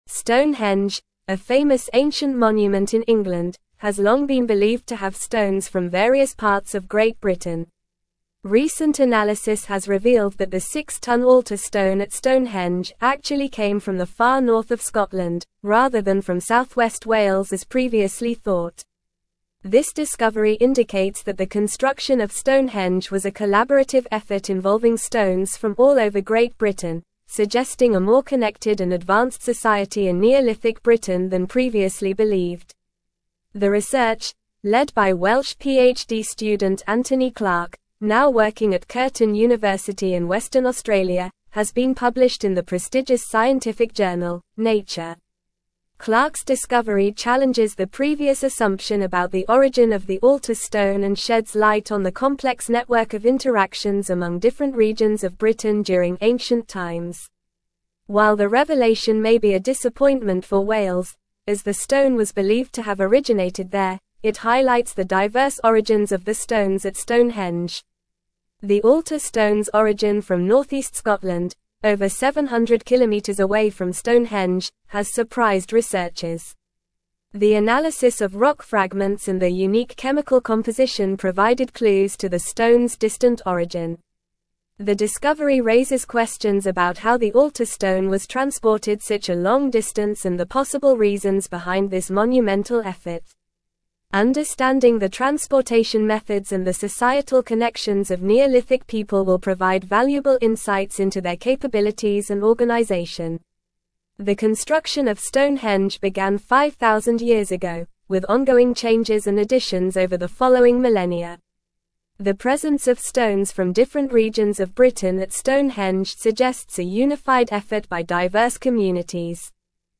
Normal
English-Newsroom-Advanced-NORMAL-Reading-Stonehenge-Altar-Stone-Originates-from-Scotland-Not-Wales.mp3